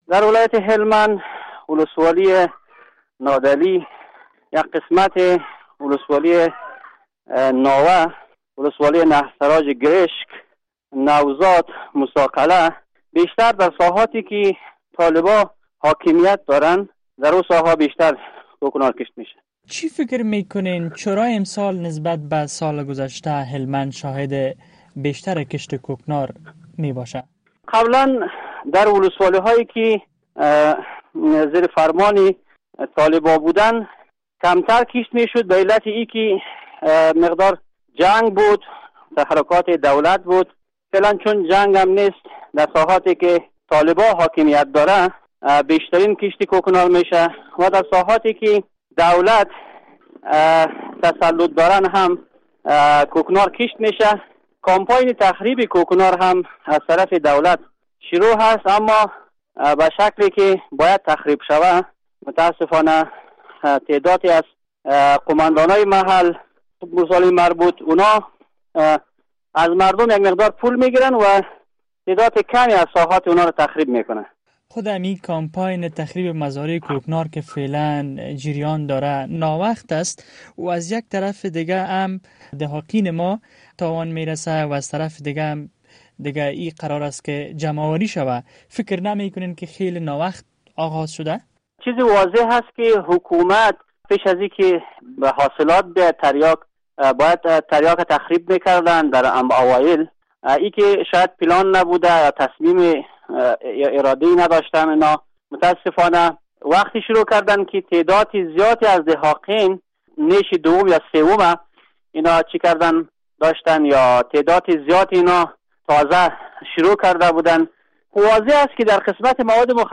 امسال باردیگر در ولایت هلمند به پیمانه زیاد کوکنار کشت شده است. در مصاحبه با میرزا حسین علی‌زاده عضو پیشین شورای ولایتی هلمند روی عوامل افزایش کوکنار در سال جاری در این ولایت، بیشتر صحبت می‌کنیم.